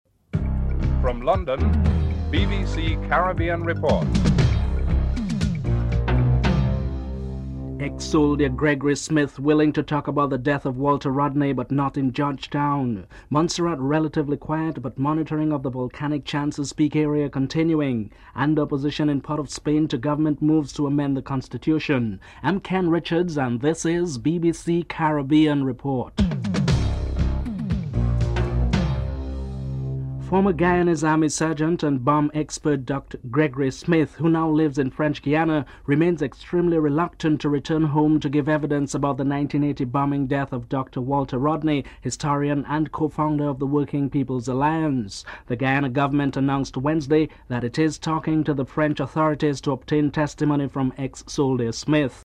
In Montserrat, a British guard ship docked as part of contingency plans in the event the volcano at Chances Peak erupts. Governor Frank Savage comments on the situation following a series of explosions. Caribbean and Latin American leaders meet at the Defense Ministerial of the Americas to discuss hemispheric security issues.